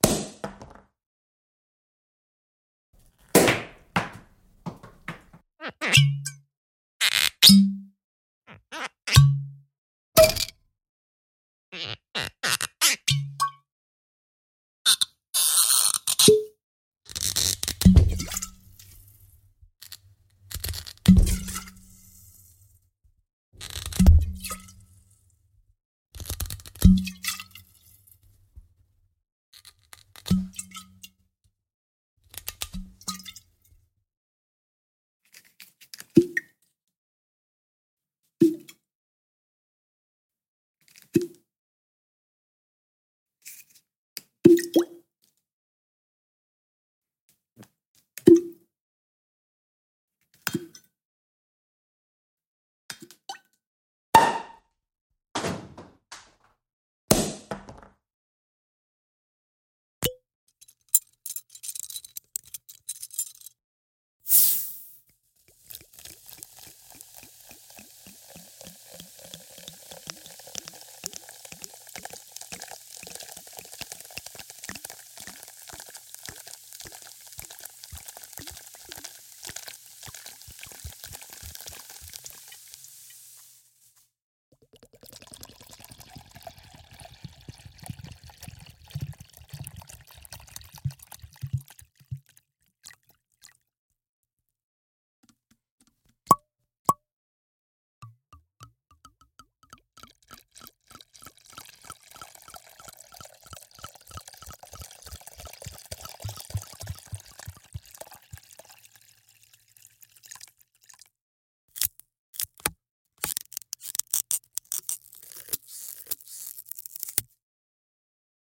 Звуки шампанского в разных вариациях